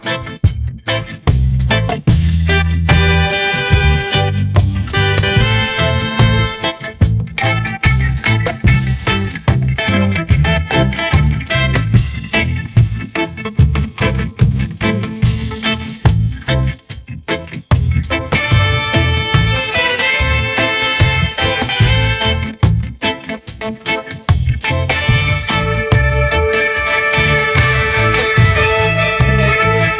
O que foi solicitado a todos: Criar uma faixa clássica de reggae com um solo de guitarra.